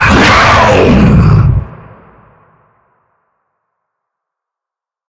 sounds / monsters / lurker / die_2.ogg
die_2.ogg